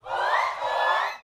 SHOUTS15.wav